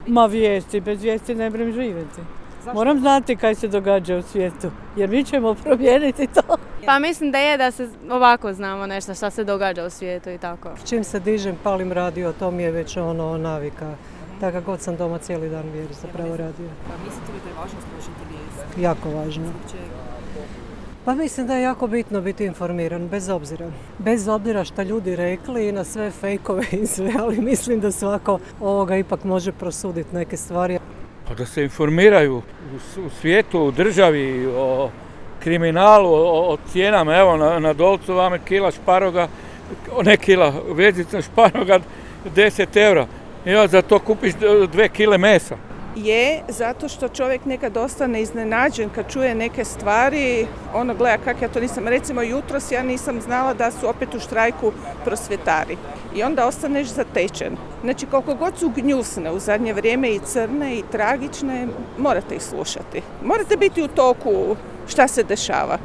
Koliko su im važne vijesti i koliko je u današnje vrijeme važno biti informiran iz pouzdanih izvora, pitali smo naše građane koji, kako su nam sami rekli, upravo iz tog razloga slušaju vijesti Media servisa.